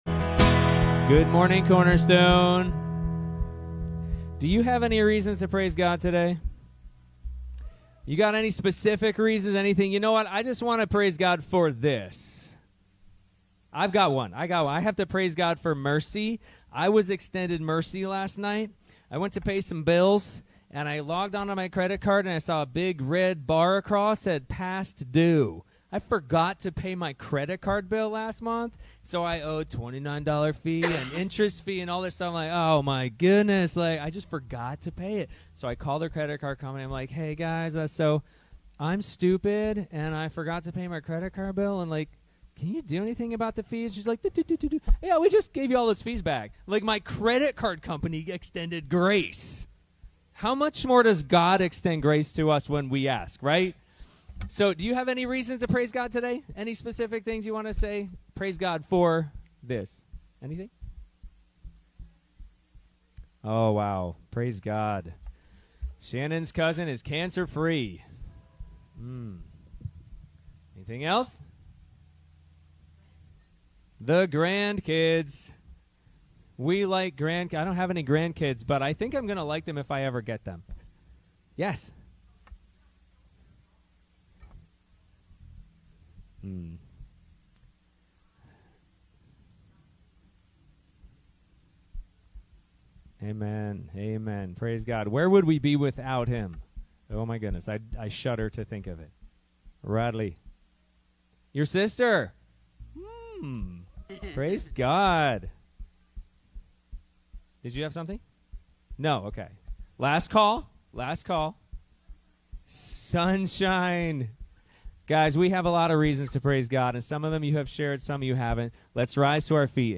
Ephesians 1:15-23 Service Type: Sunday Morning Youversion Event I WANT TO KNOW GOD BETTER… …to know the HOPE to which He has called me.